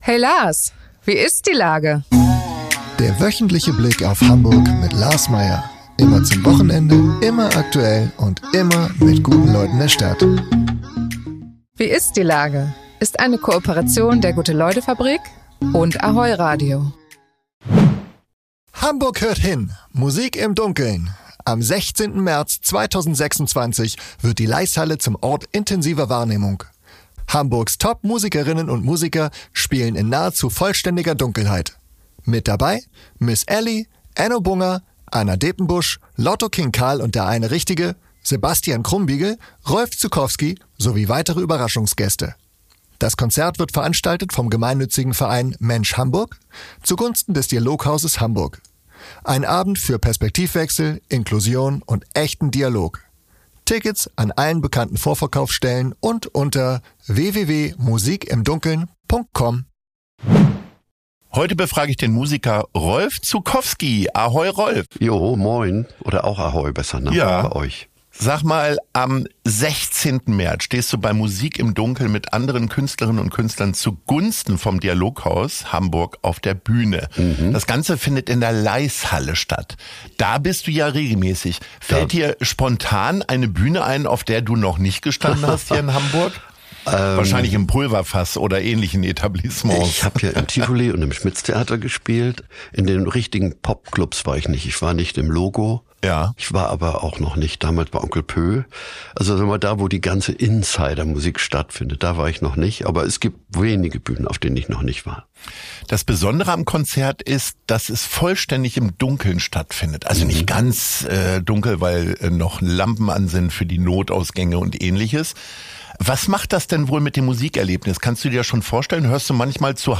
In der aktuellen Folge von „Wie ist die Lage?“ ist Rolf Zuckowski zu Gast.